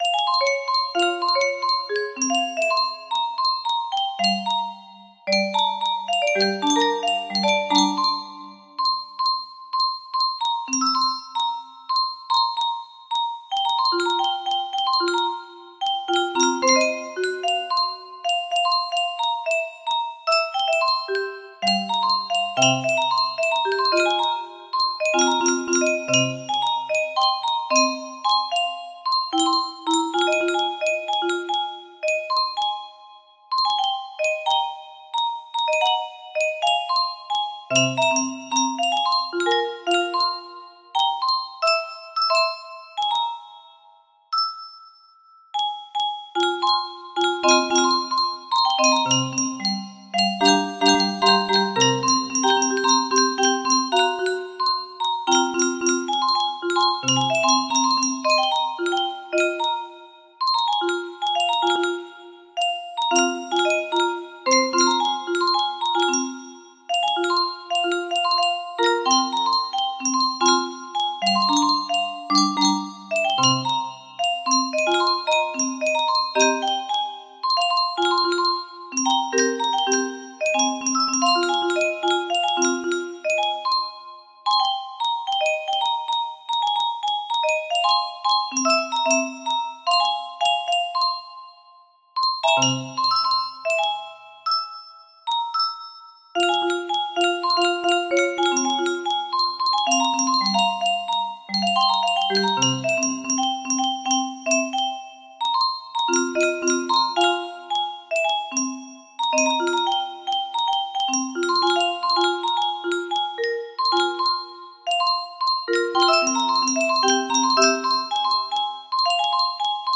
compose _kayoung music box melody